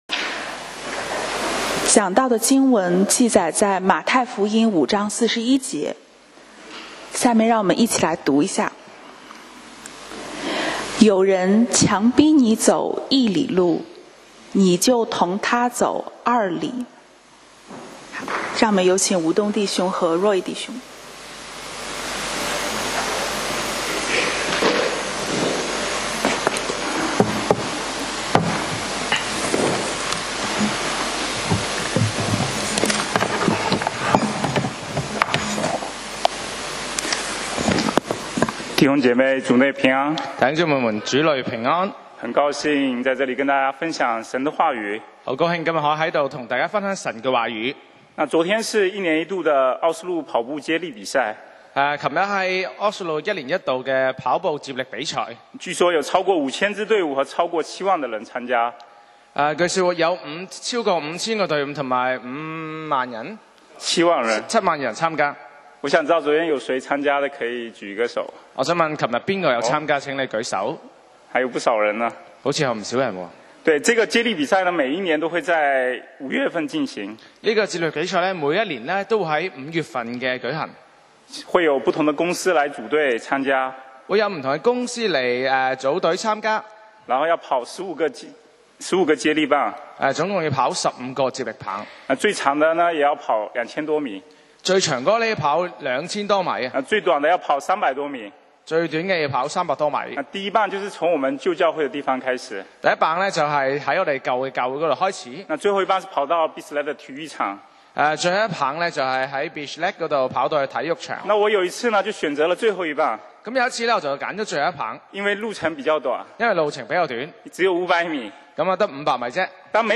講道 Sermon 題目 Topic：多走一里路 經文 Verses：太5:41. 有人强逼你走一里路，你就同他走二里。